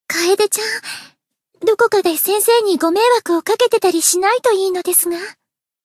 贡献 ） 分类:蔚蓝档案语音 协议:Copyright 您不可以覆盖此文件。
BA_V_Mimori_Cafe_Monolog_4.ogg